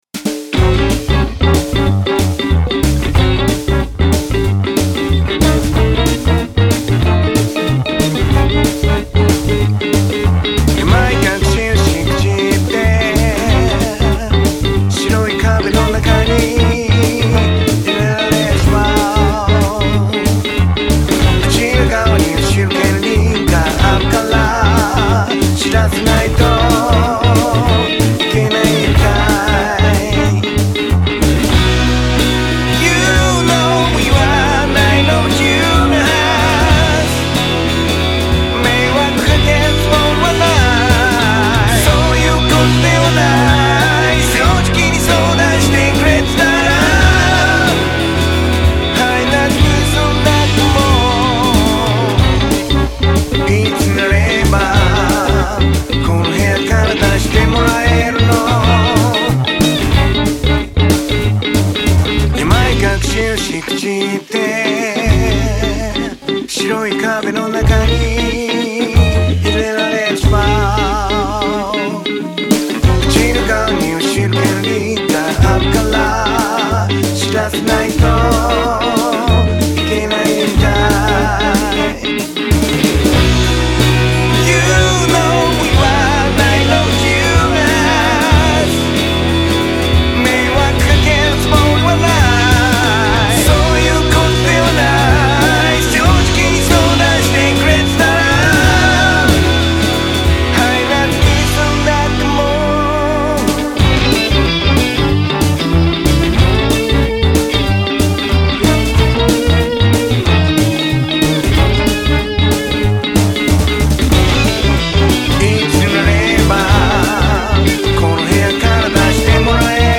毎日1曲、新曲つくってアレンジ＆録音したものを日々アップロード中。